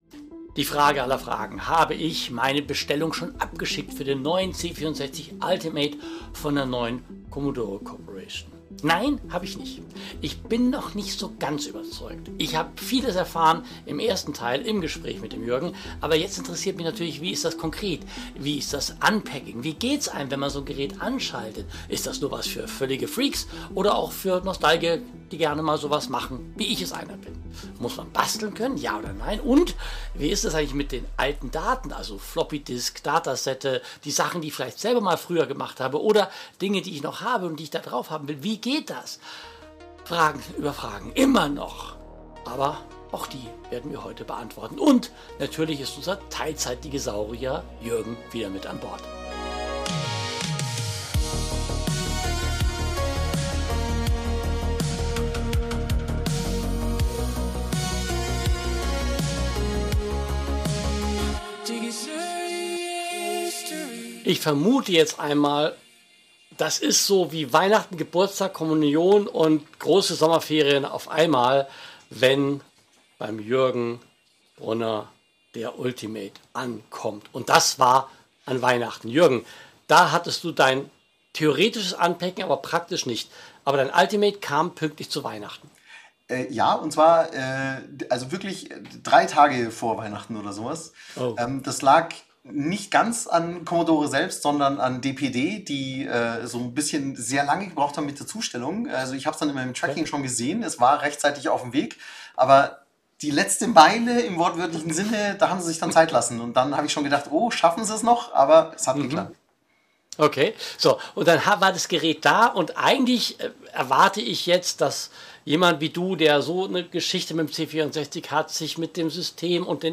Ein Gespräch zwischen hinterfragendem Journalisten und Enthusiast – ehrlich, direkt und ohne Marketing-Geschwafel.